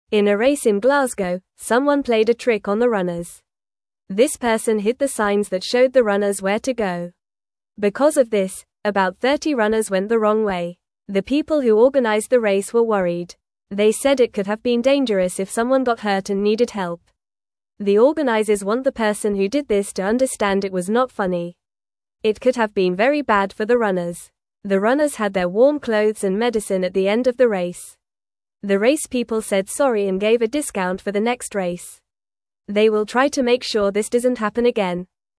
Fast
English-Newsroom-Beginner-FAST-Reading-Trickster-Confuses-Runners-in-Glasgow-Race.mp3